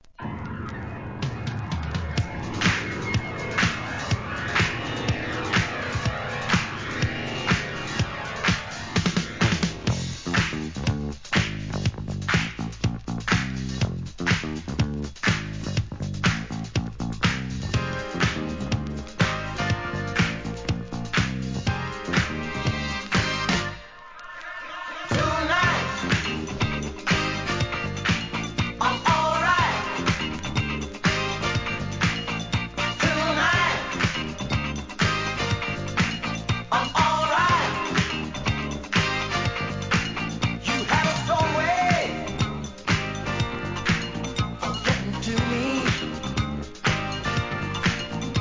SOUL/FUNK/etc... 店舗 ただいま品切れ中です お気に入りに追加 1979年、アーバン・ブギー!!